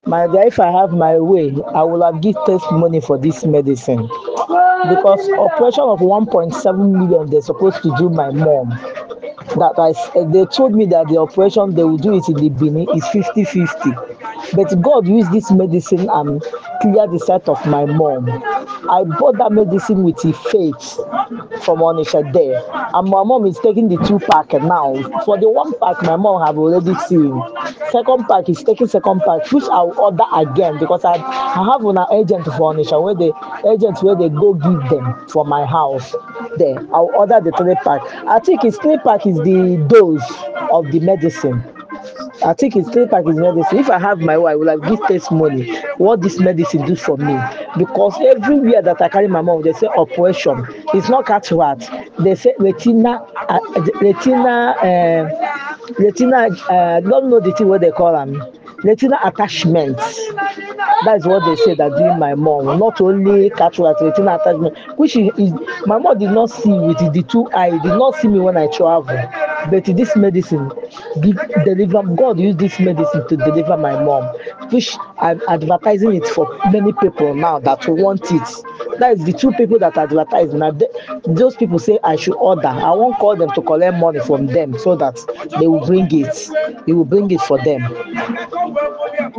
Happy Customer's Review